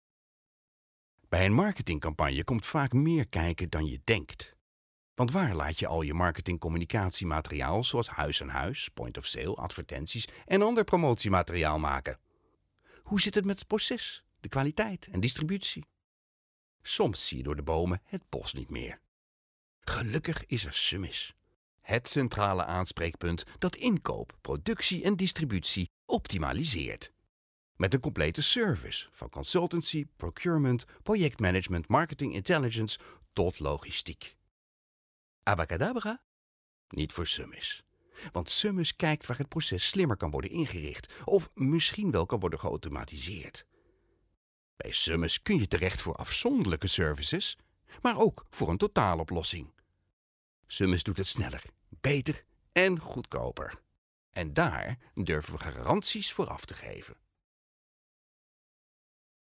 Native Dutch speaker, voice over Eurosport, warm strong voice
Sprechprobe: Industrie (Muttersprache):